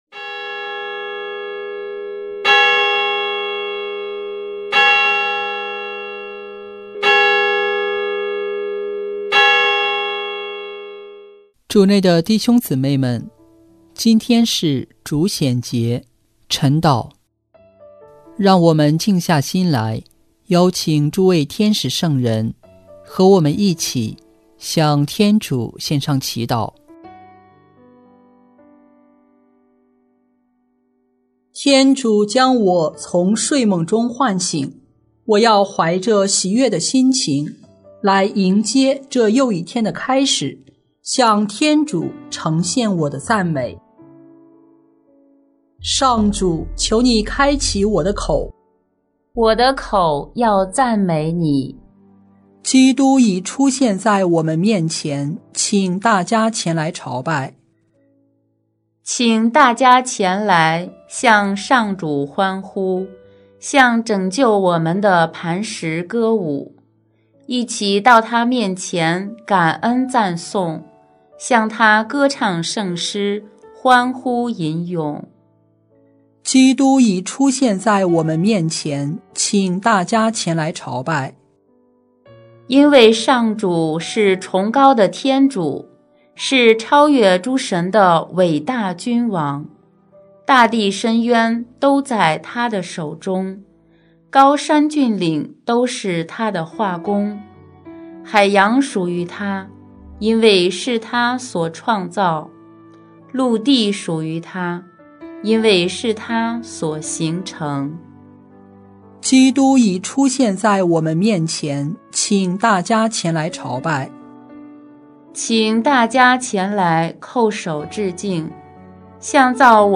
【每日礼赞】|1月4日主显节晨祷